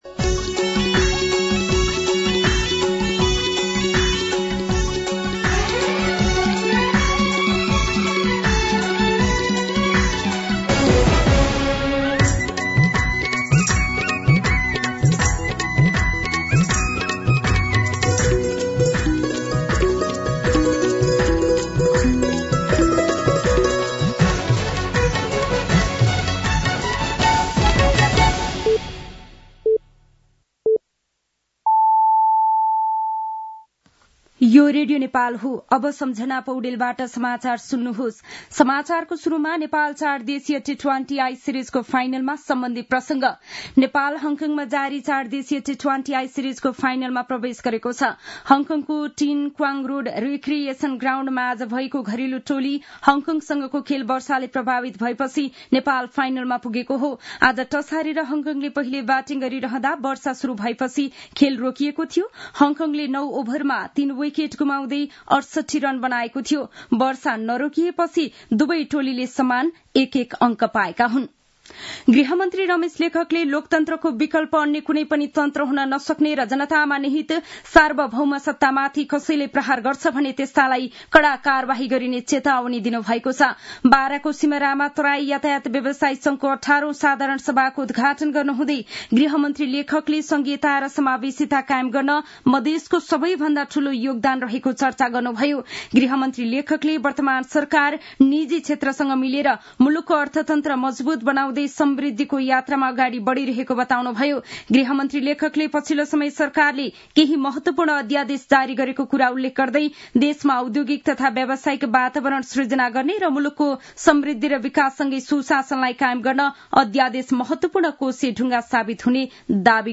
दिउँसो ४ बजेको नेपाली समाचार : ३० चैत , २०८१
4pm-Nepali-News-1.mp3